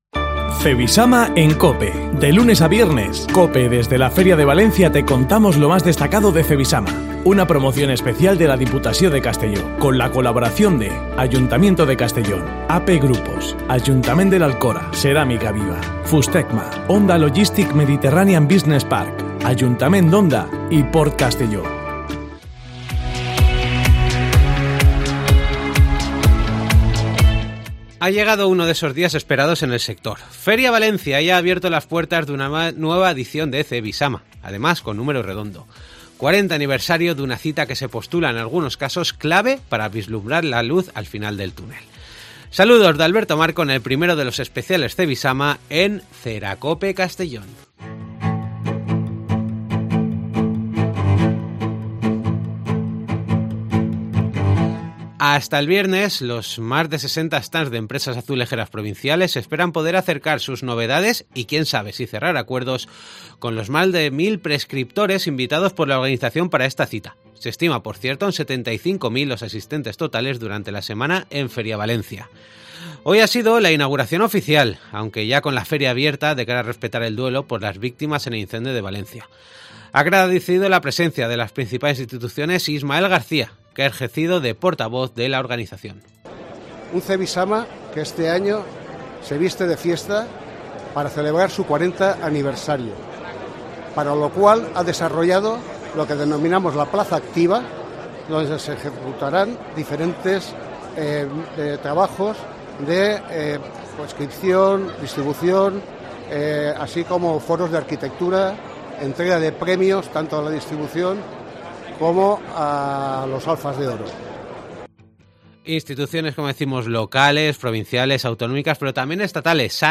Hoy primero de los especiales desde Cevisama.